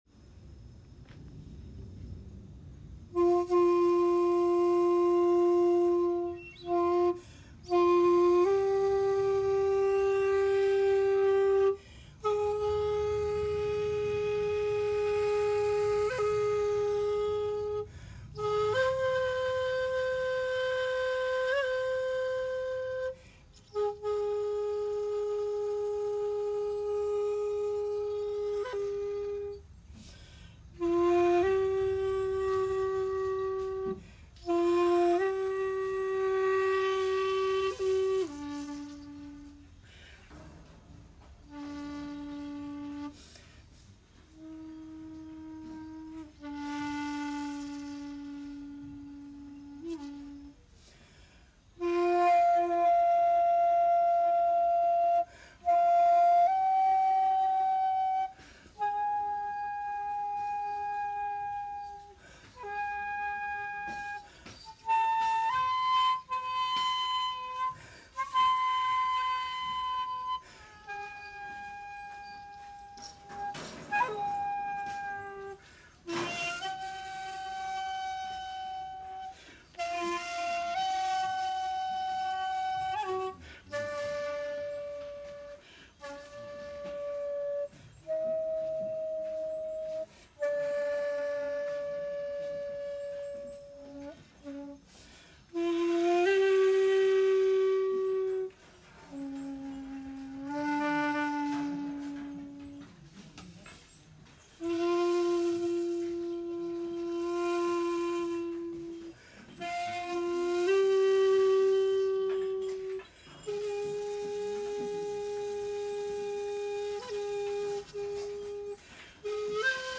この猫の前で今日の打ち止めの尺八吹奏をしました。
（音源「水鏡」）←国分寺前にて尺八、